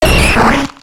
Cri d'Amonita dans Pokémon X et Y.